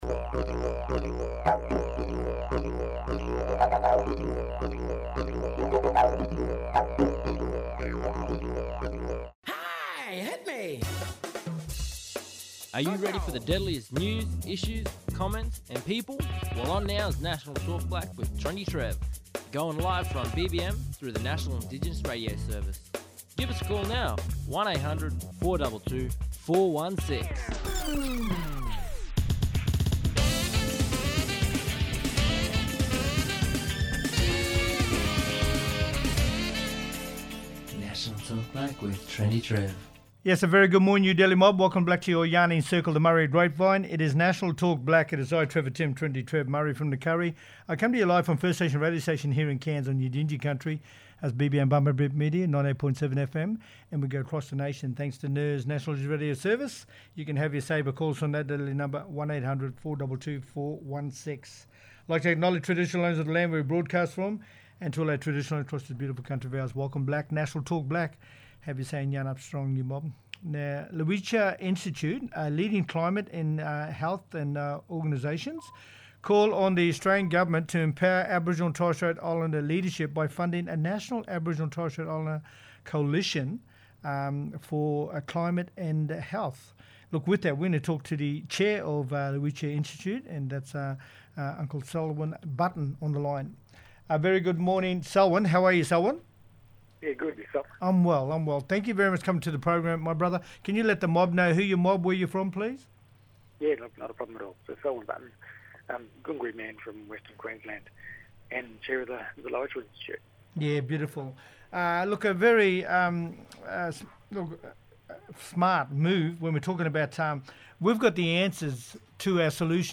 talking live from today’s Queensland Indigenous Land and Sea Rangers Conference at Tjapukai